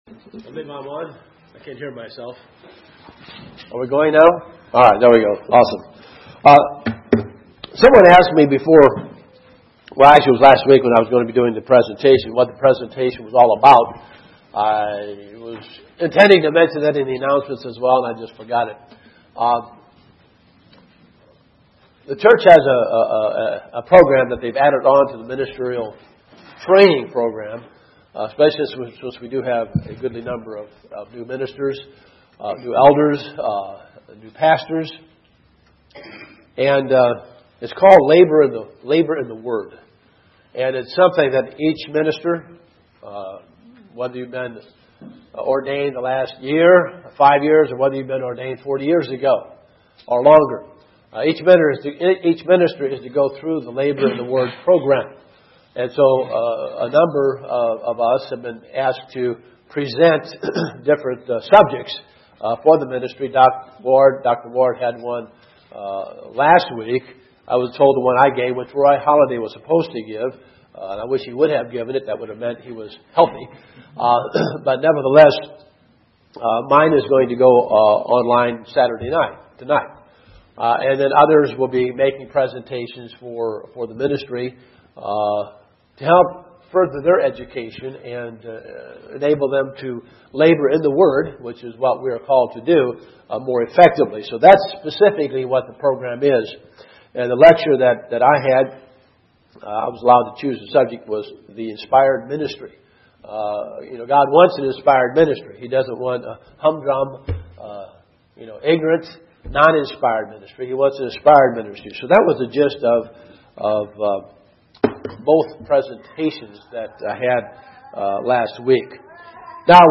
Given in Dayton, OH
UCG Sermon Studying the bible?